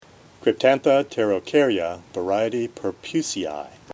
Pronunciation/Pronunciación:
Cryp-tán-tha pte-ro-cár-ya var. pur-pùs-i-i